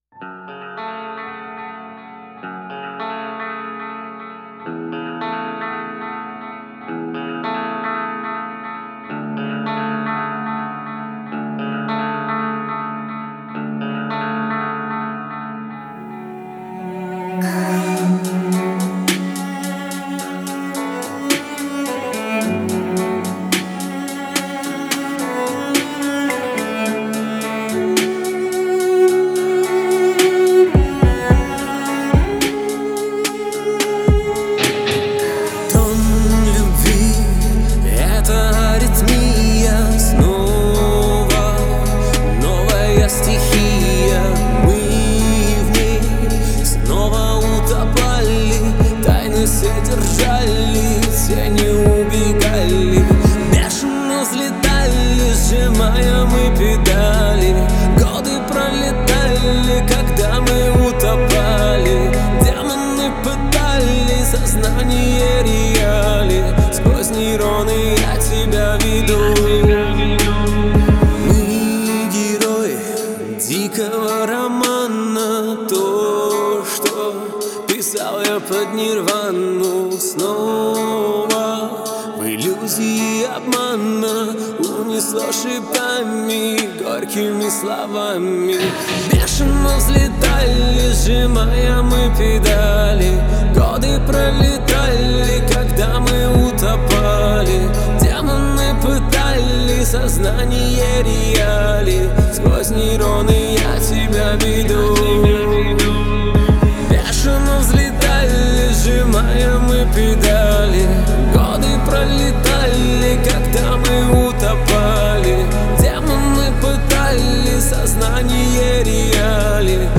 выполненная в жанре хип-хоп с элементами поп.